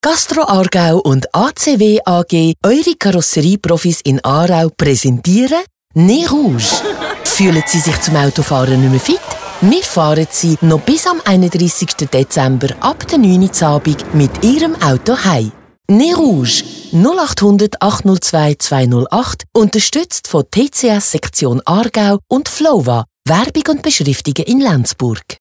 Radiospot_Nez_Rouge_Aargau_2025.mp3